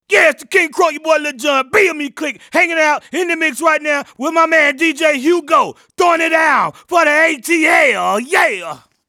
here's one of the drops he did